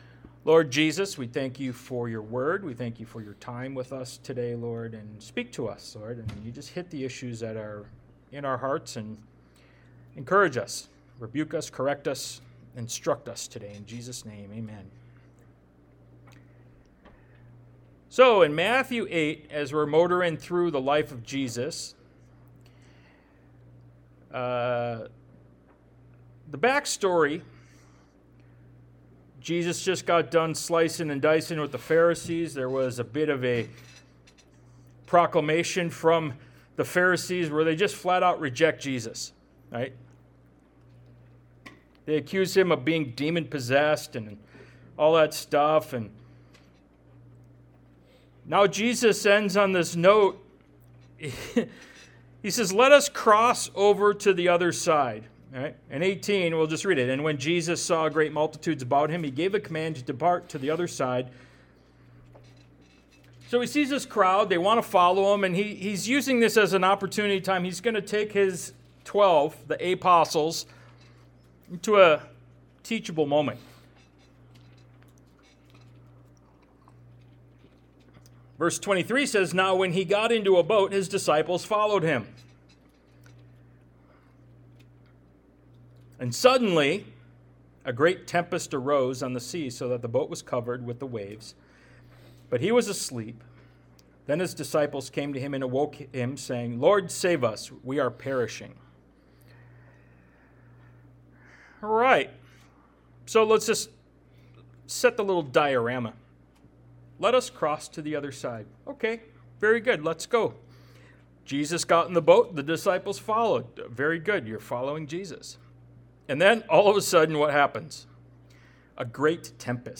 Ministry of Jesus Service Type: Sunday Morning « “Bring Out Your Treasure” Ministry of Jesus Part 29 Are You Desperate?